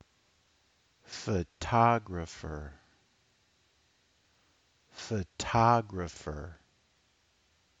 pho-TO-gra-pher
[unstressed] + [primary] + [unstressed] + [unstressed]